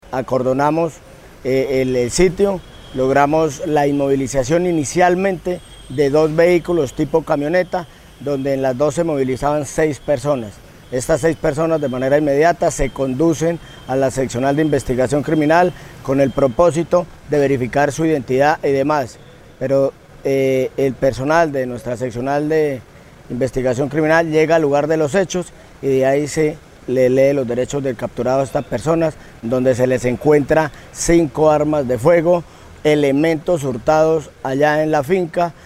El coronel Julio Guerrero, comandante de la Policía Caquetá, explicó que, con una supuesta orden de allanamiento y registro ingresaron a la vivienda e intimidaron a sus moradores con las armas de fuego y empezaron a buscar dinero y elementos de valor; hurtando celulares y dinero en efectivo.